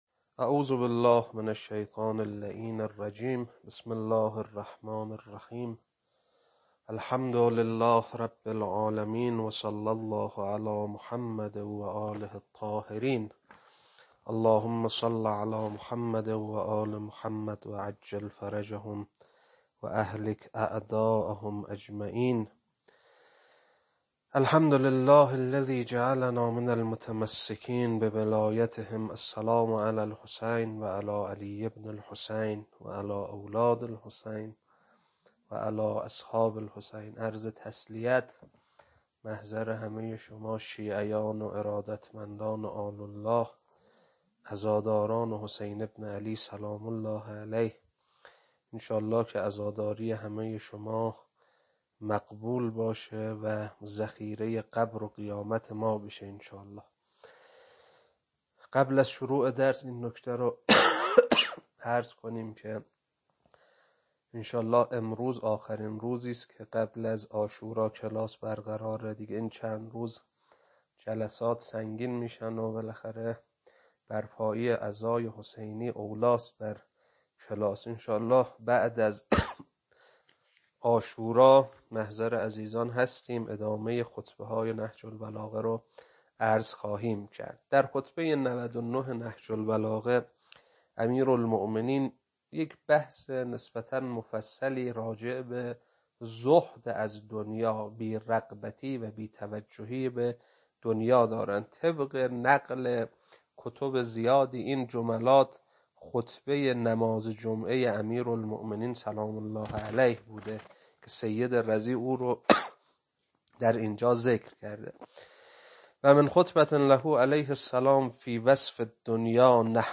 خطبه99.mp3